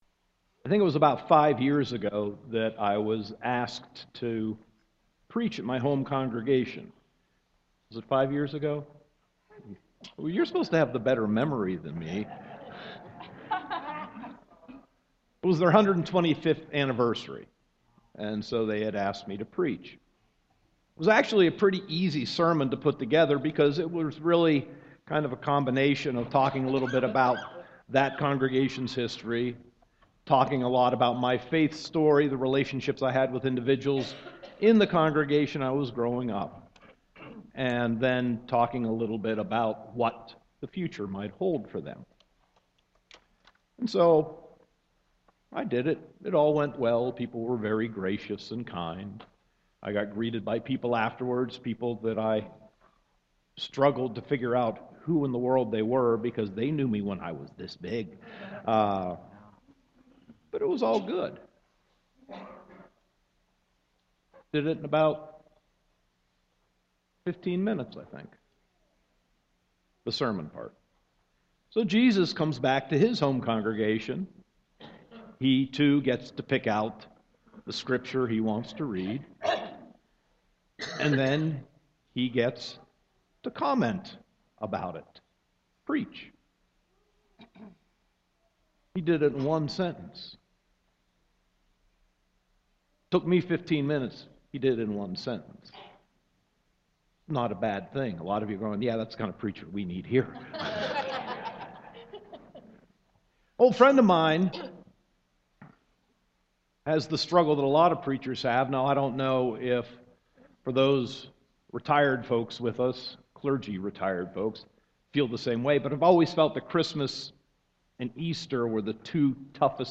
Sermon 1.24.2016